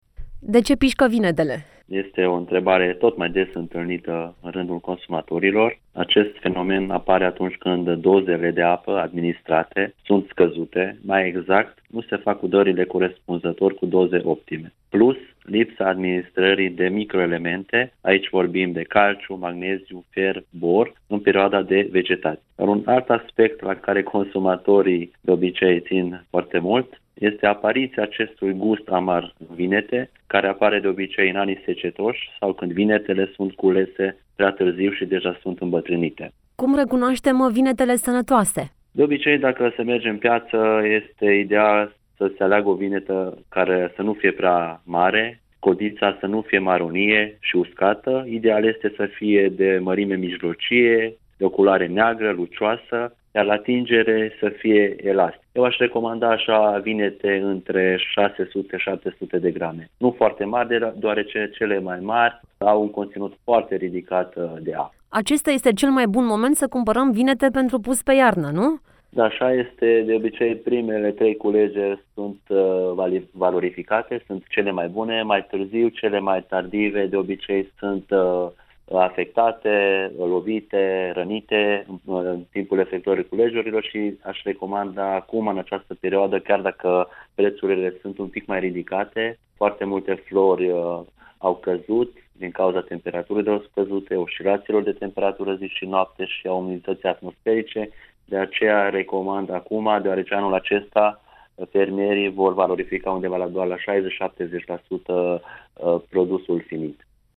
Despre aceasta, dar și despre motivele pentru care unele vinete au gust amar, în interviul de mai jos: